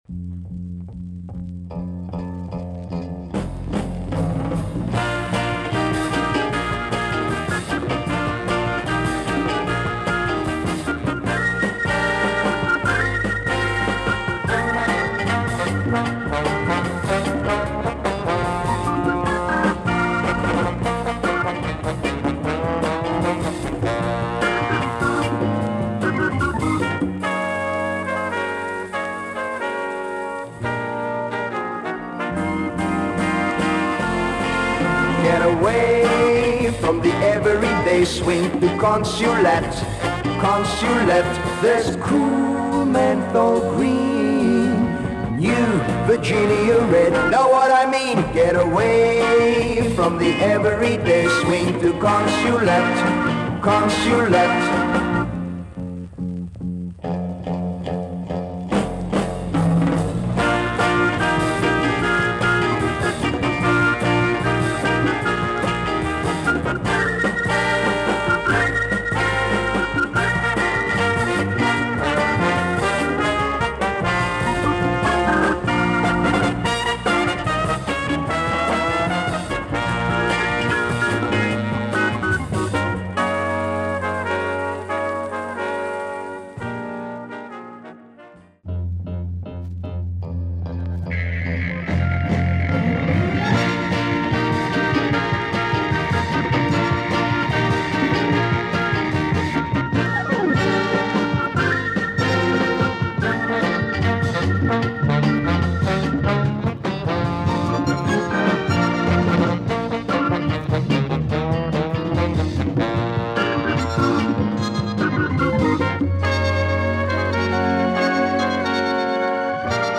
Three organ pop tunes